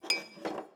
Babushka / audio / sfx / Kitchen / SFX_Glass_01_Reverb.wav
SFX_Glass_01_Reverb.wav